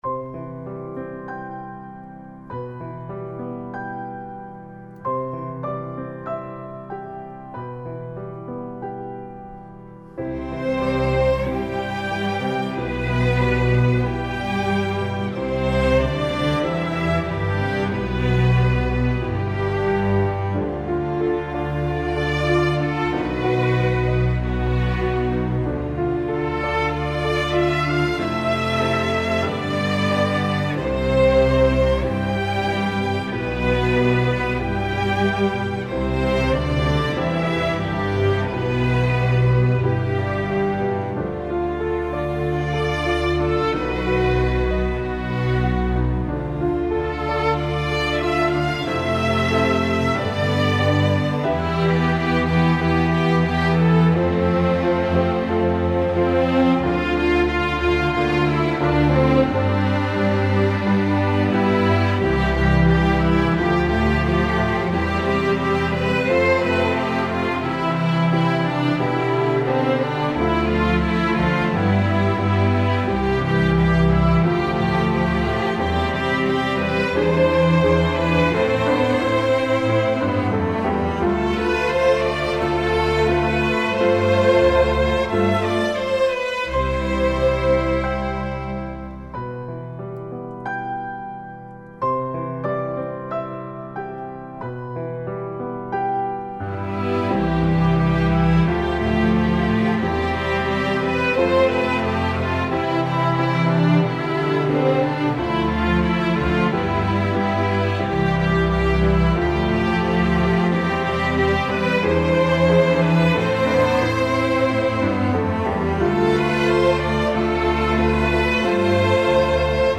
Piano accompaniment part: